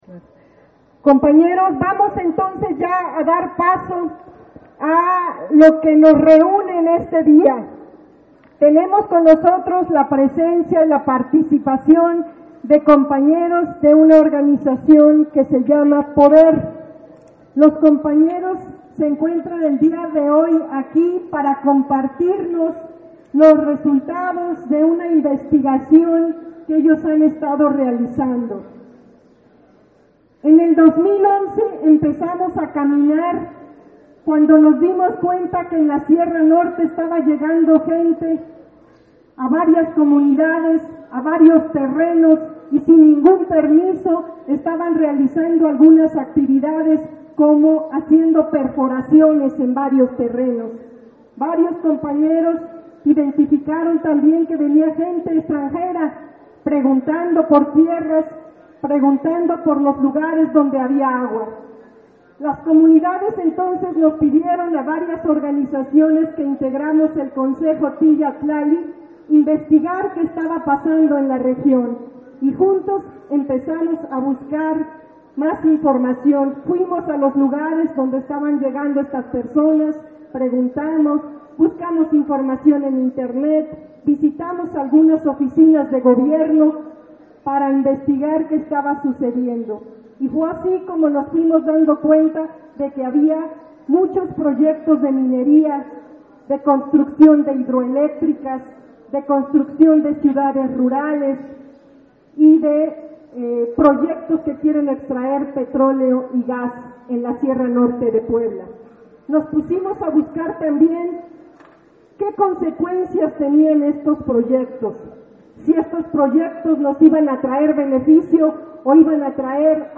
Este 22 de junio se llevó a cabo el foro Proyectos de muerte en la sierra norte de Puebla y su impacto en los Derechos Humanos, en la cancha municipal de Ixtacamaxtitlán en la Sierra Norte de Puebla (SNP), al cual acudieron más de mil personas, habitantes y autoridades de más de 20 comunidades de esa zona.
Bienvenida por parte del Consejo Tiyat Tlali